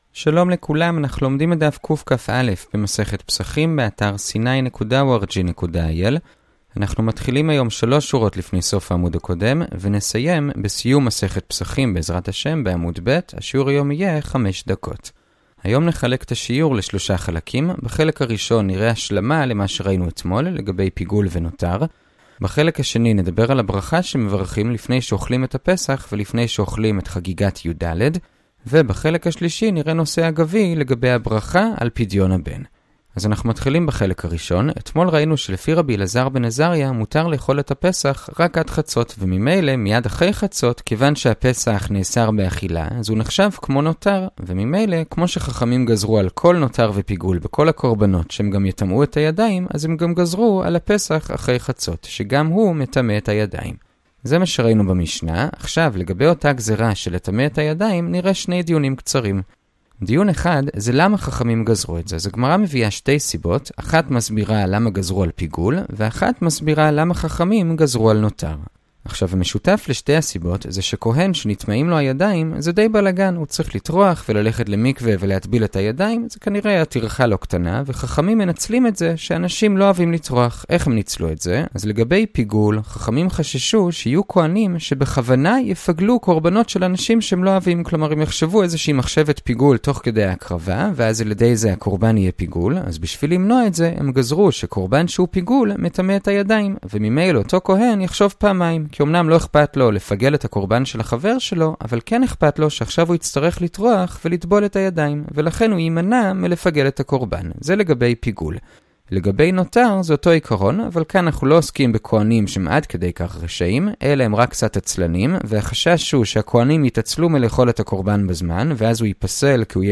שיעור גמרא פשוט וברור כפי שמעולם לא שמעת. וב15 דקות בלבד.